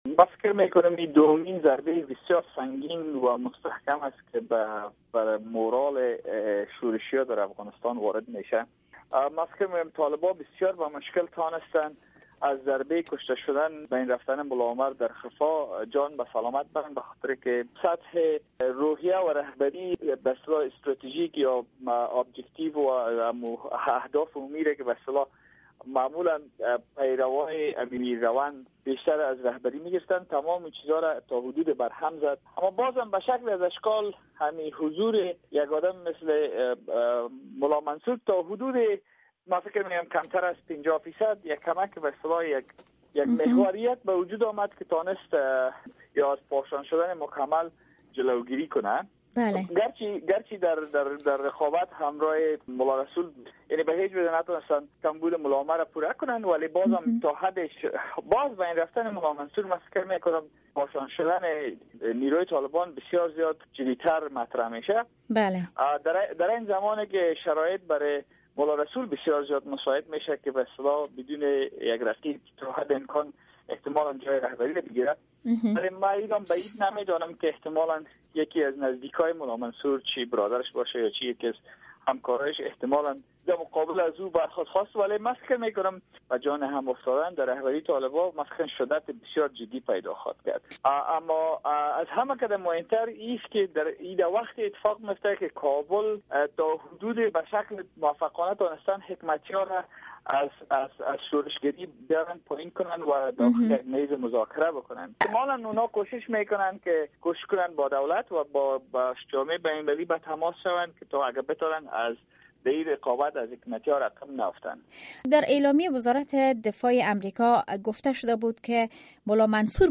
مصاحبه صدای امریکا